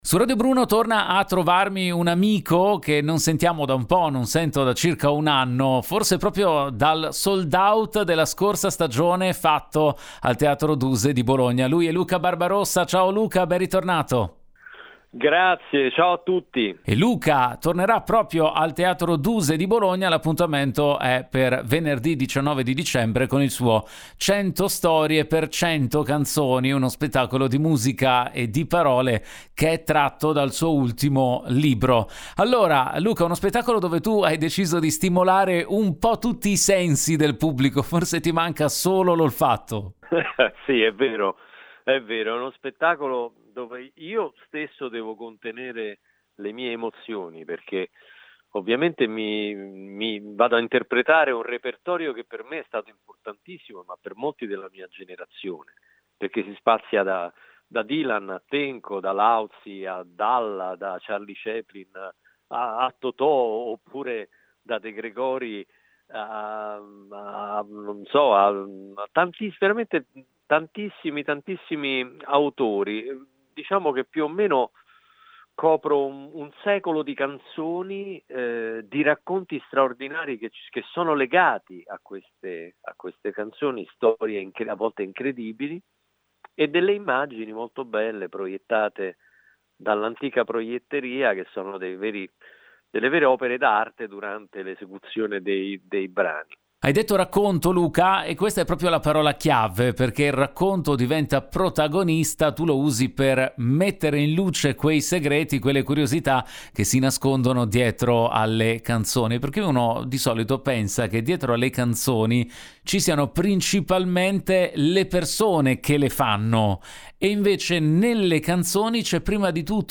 Home Magazine Interviste Luca Barbarossa presenta “Cento storie per cento canzoni”